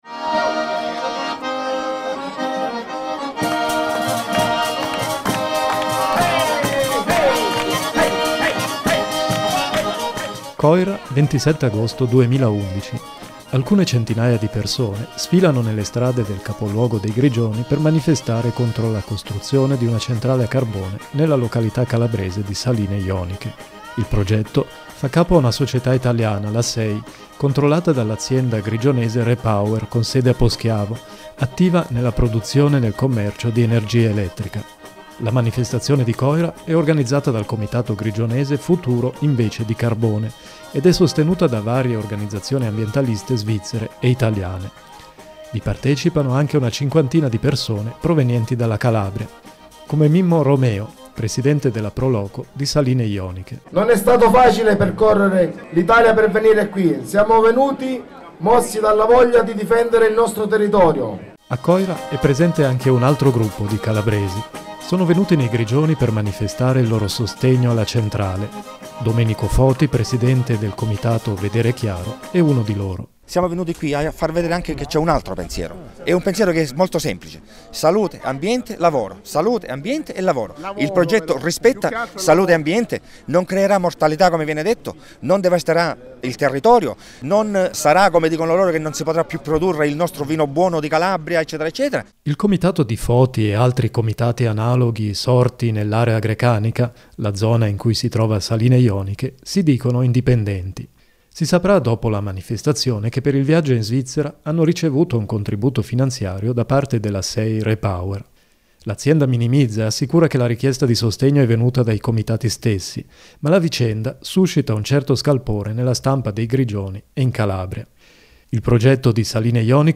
Laser RSI Rete 2: Reportage a Saline Joniche